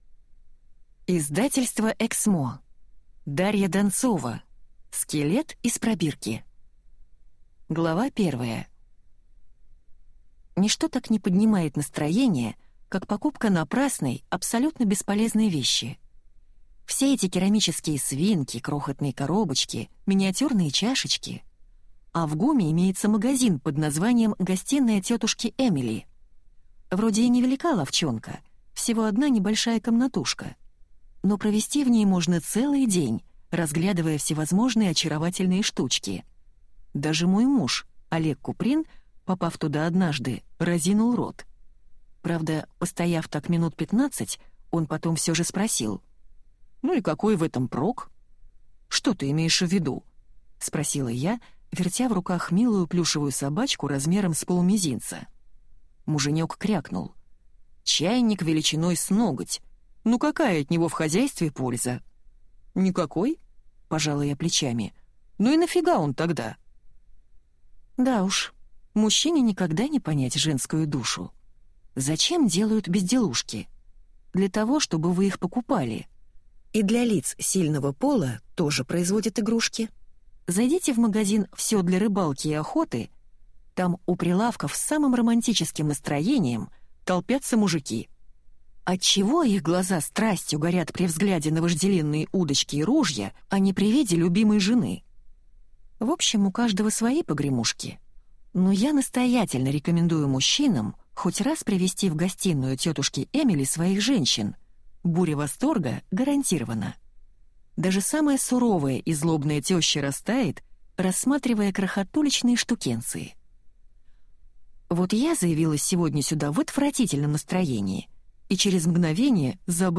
Аудиокнига Скелет из пробирки | Библиотека аудиокниг